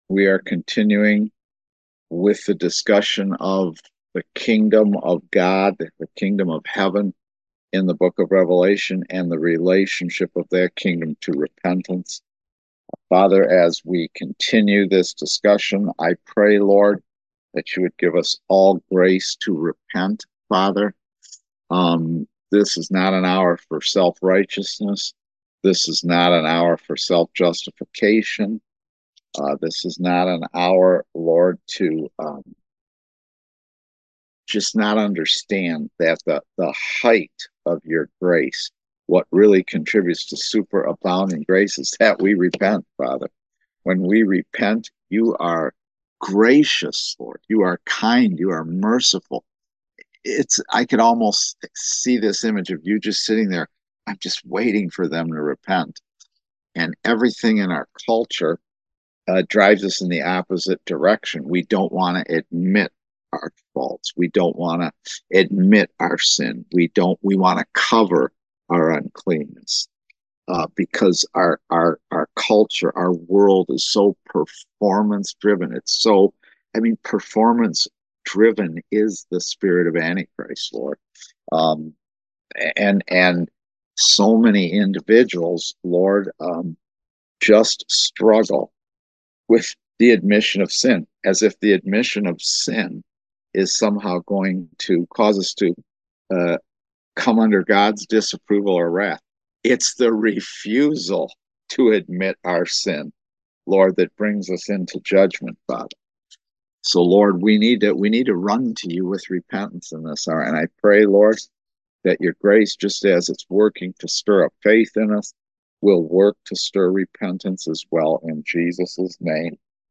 Series: Eschatology in Daniel and Revelation Service Type: Kingdom Education Class